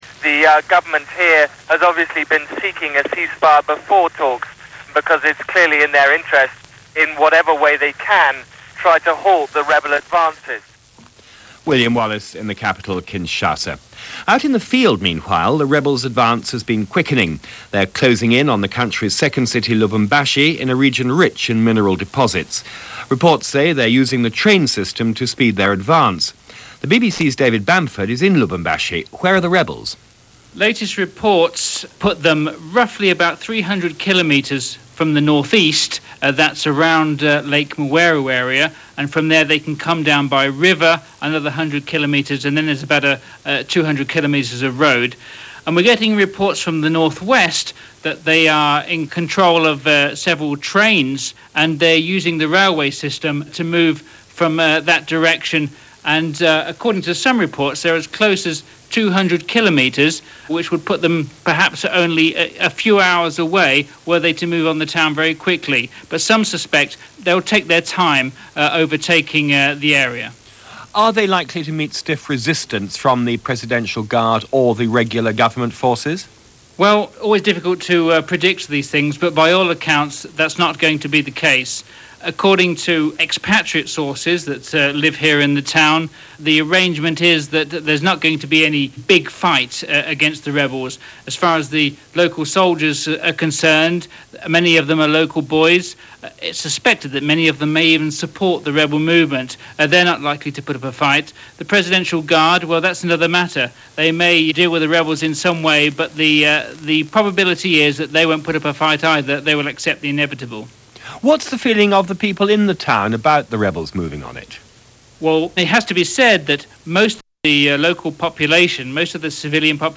Marching On Kisangani - Al Gore To Beijing - The English Patient Sweeps Oscars - March 25, 1997 - Newsdesk - BBC World Service